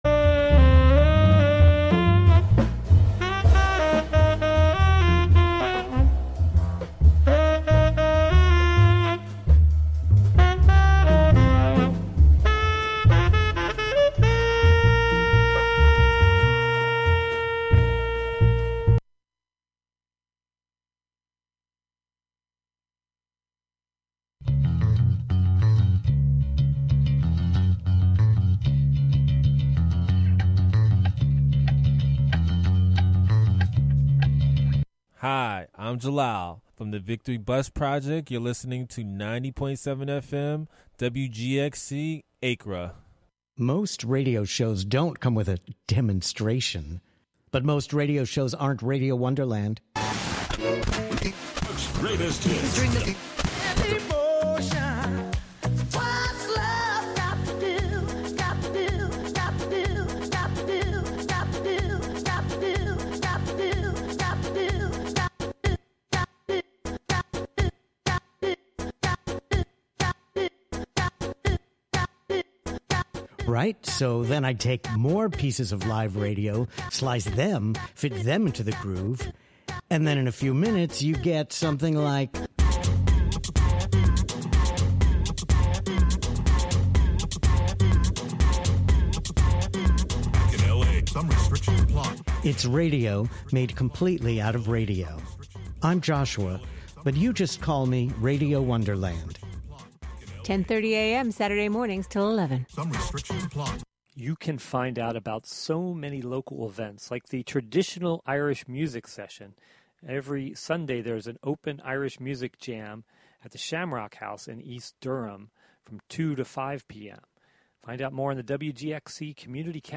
Contributions from many WGXC programmers.